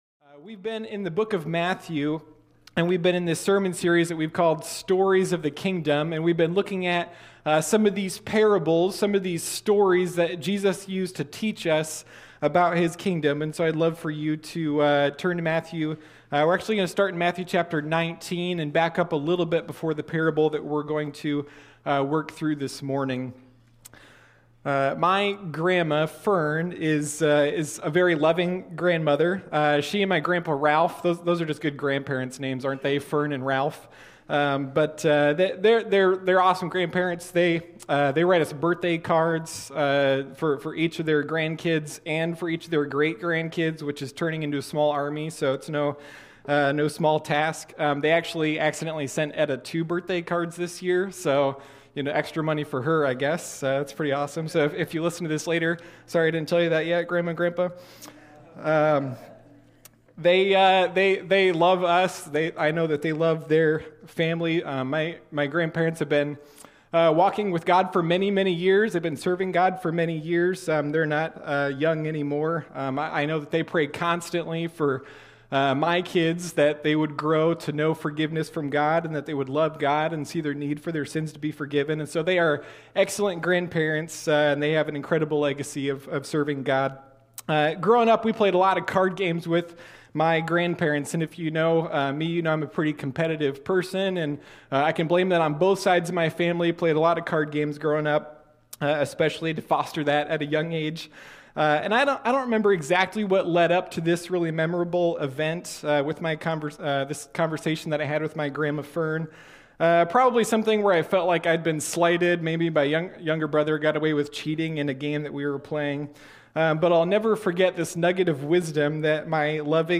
Sunday Morning Stories of the Kingdom